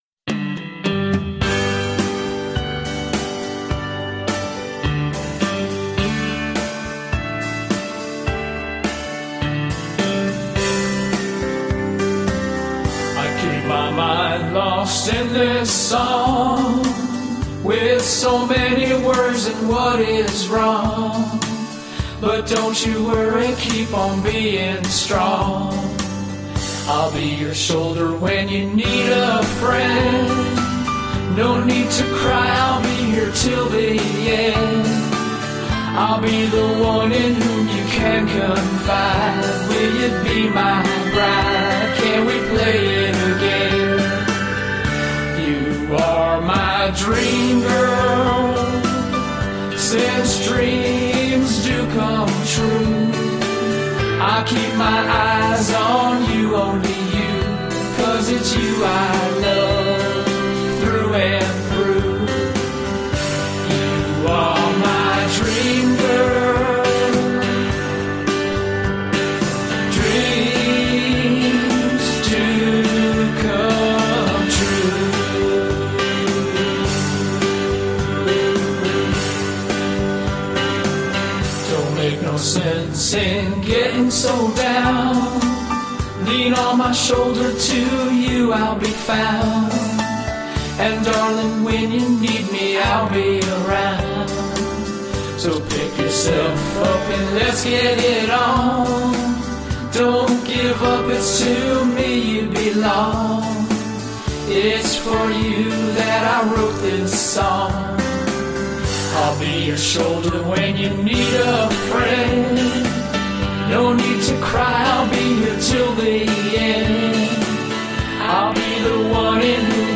• 5 hours in the studio
• One vocal take
Lead Guitar
Backing Vocals